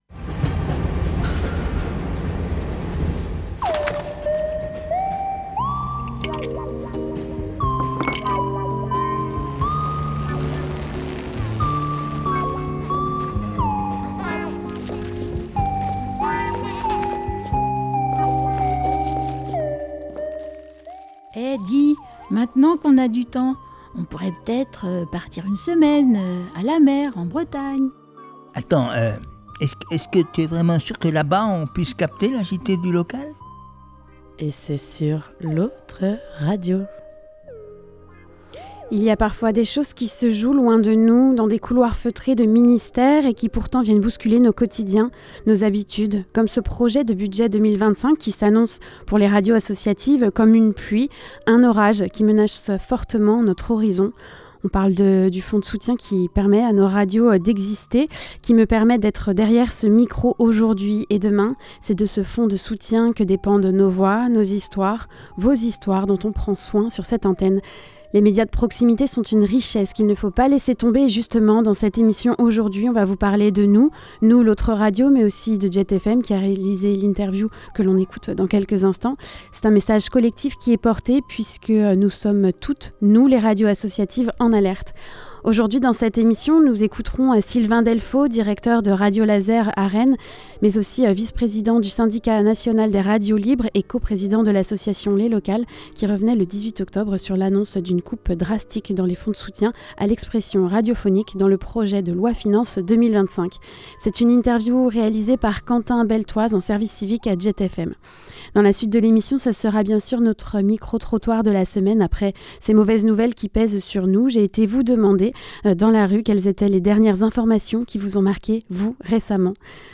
Le Micro Trottoir